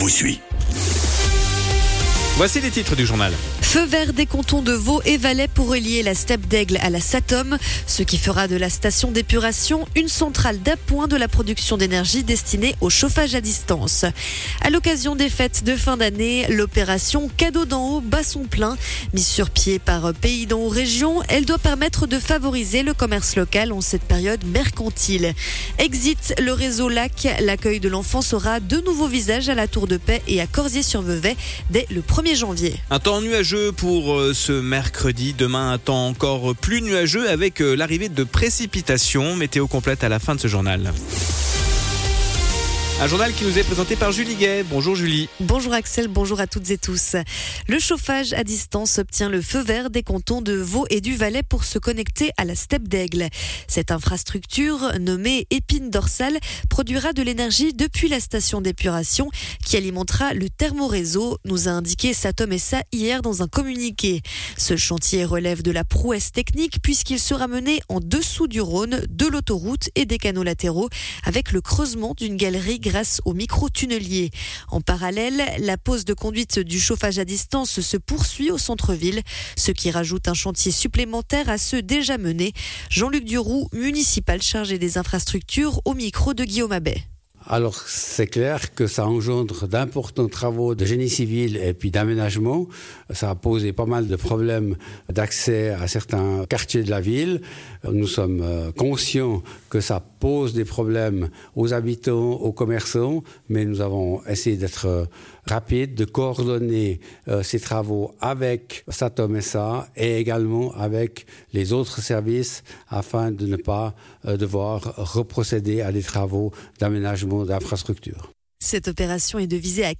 Le journal de midi du 18.12.2024